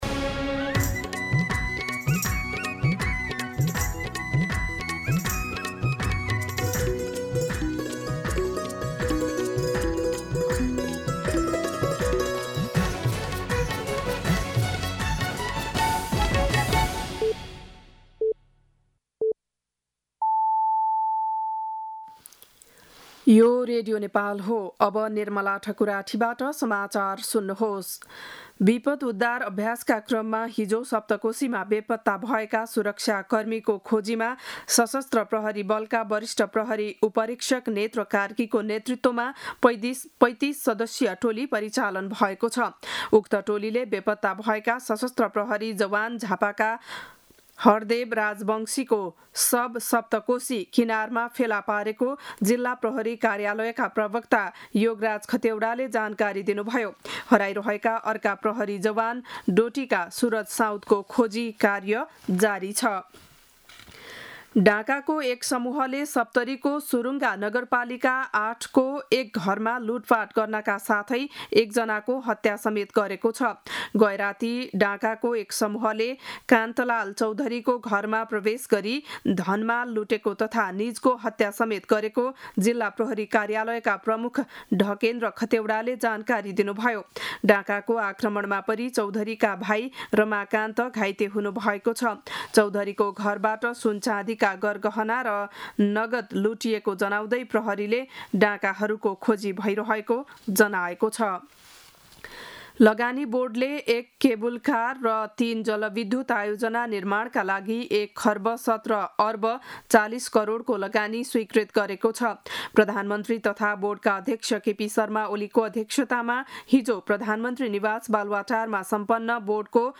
बिहान ११ बजेको नेपाली समाचार : २३ जेठ , २०८२